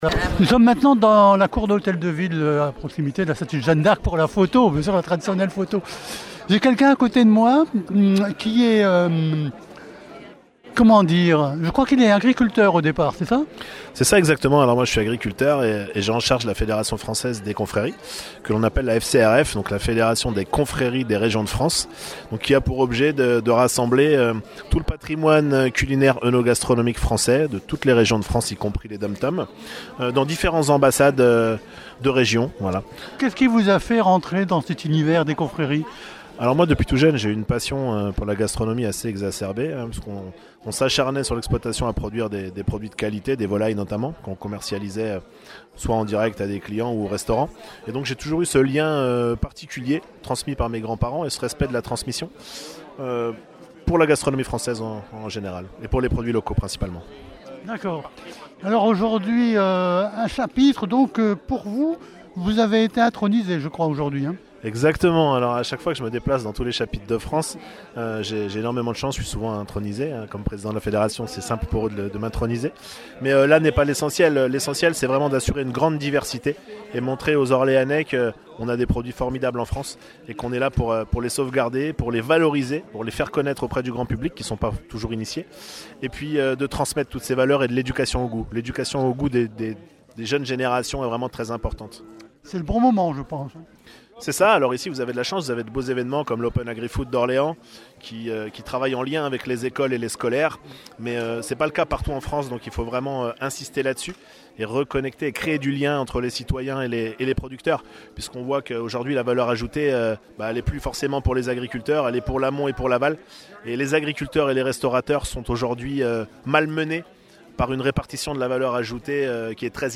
VAG MUSIC-CONFRERIES - INTERVIEW NO 4